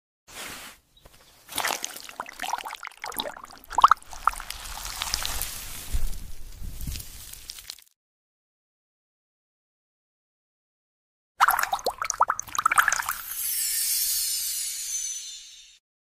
Miniature Forest Bloom Glass Fruit sound effects free download
forest ASMR